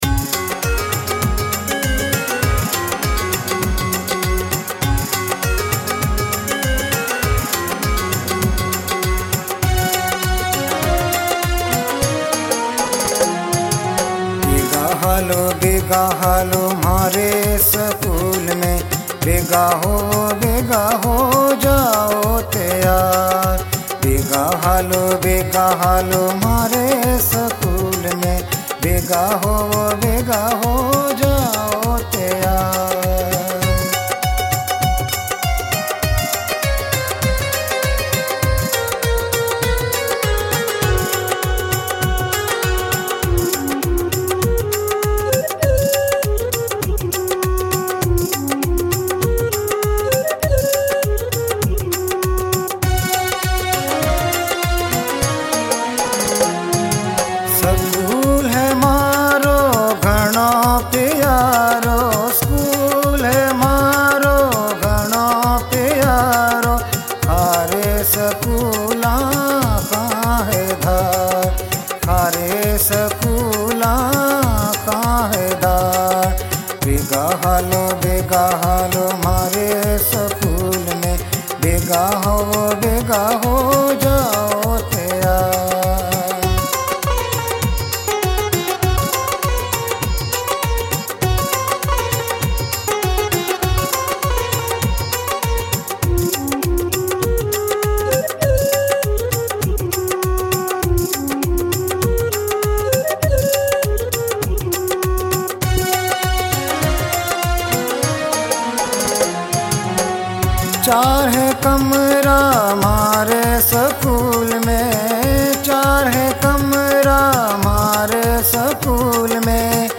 کثیرالسانی تعلیمی گیت | مارواڑی ٻولی، رِیت اَن تعلیم